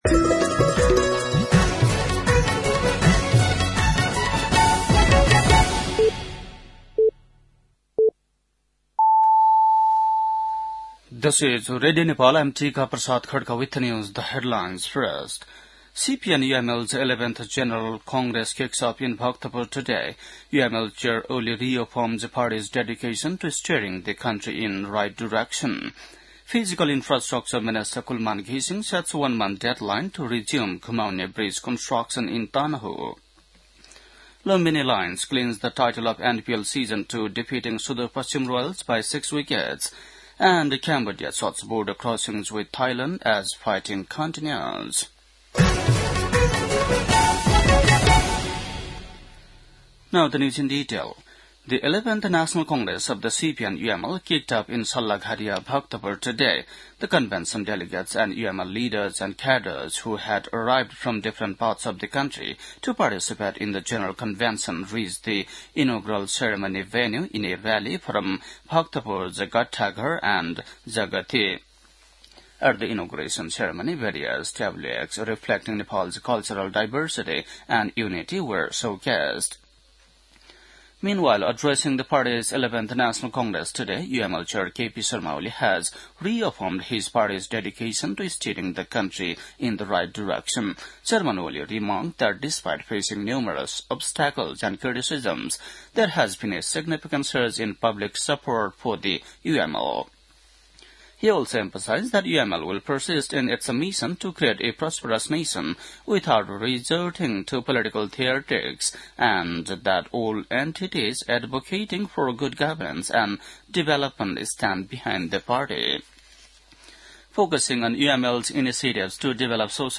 बेलुकी ८ बजेको अङ्ग्रेजी समाचार : २७ मंसिर , २०८२
8.-pm-english-news-1-1.mp3